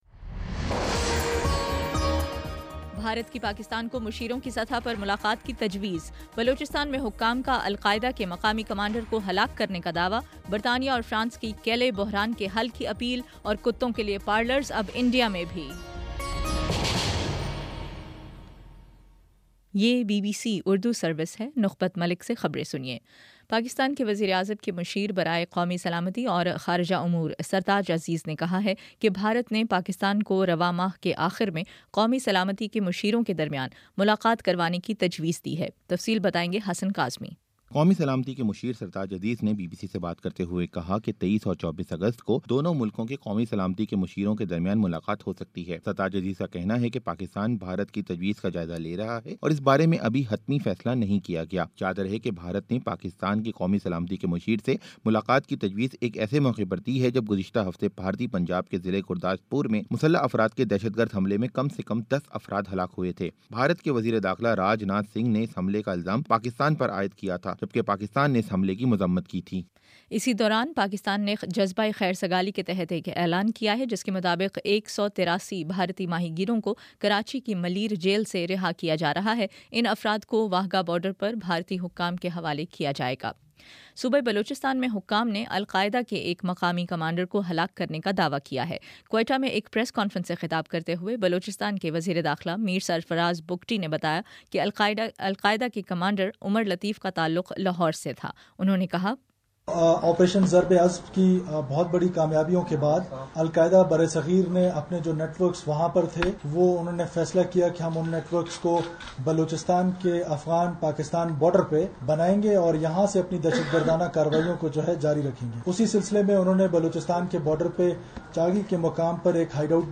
اگست 02: شام چھ بجے کا نیوز بُلیٹن